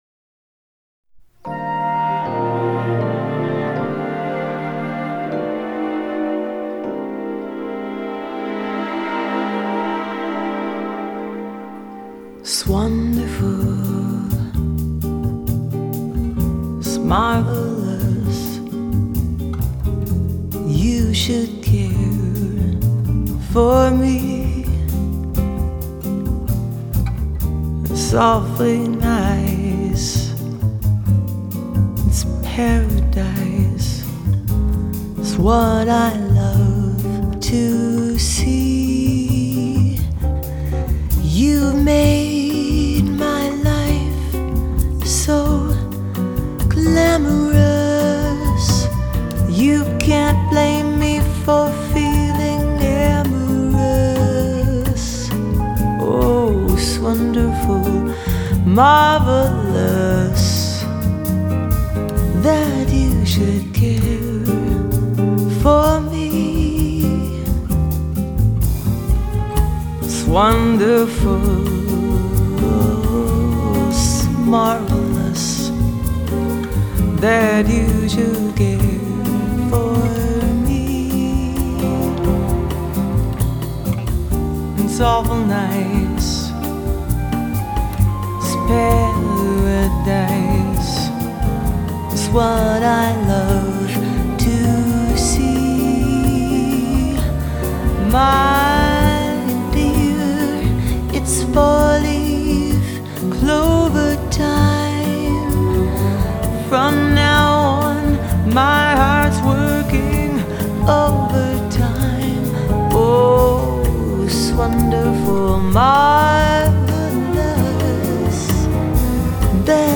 Jazz, Vocal Jazz, Smooth Jazz